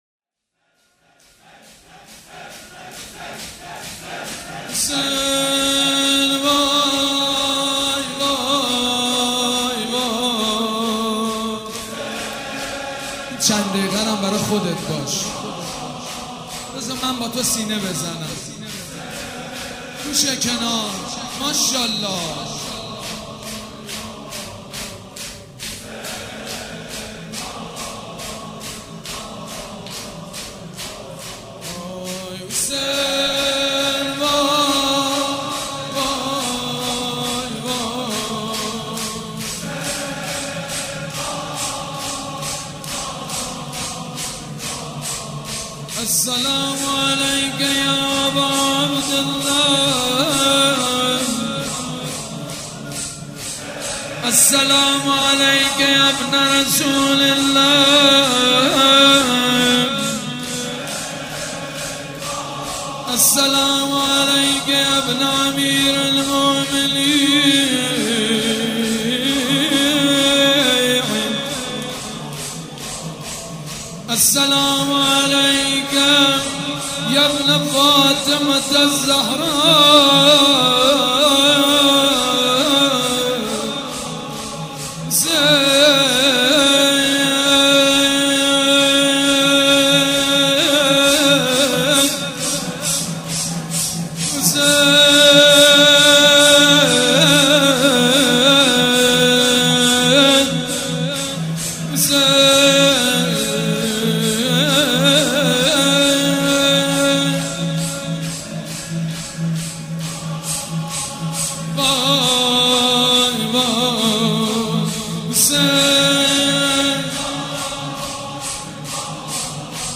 شب هشتم محرم الحرام‌ یکشنبه ۱8 مهرماه ۱۳۹۵ هيئت ريحانة الحسين(س)
سبک اثــر شور مداح حاج سید مجید بنی فاطمه
مراسم عزاداری